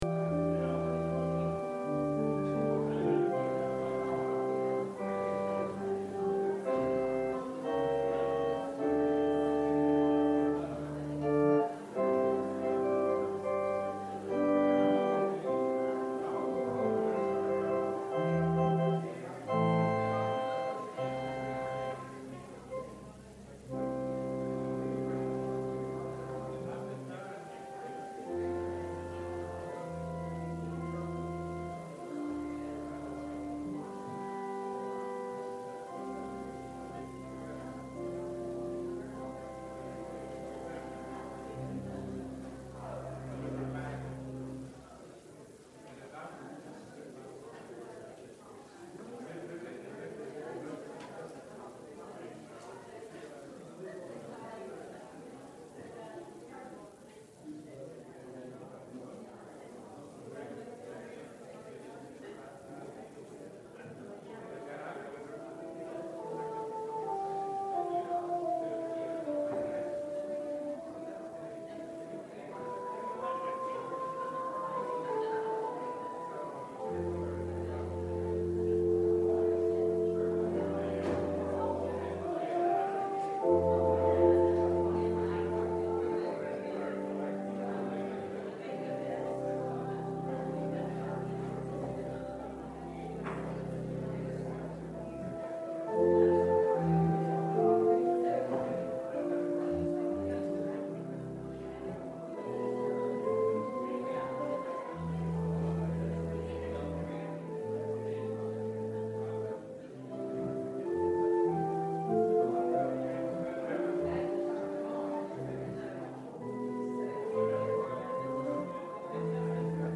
John Service Type: Sunday Worship Topics: Donkey , Hosanna , Jesus , Messiah , Palm « Glimpses of Glory Believe It or Not!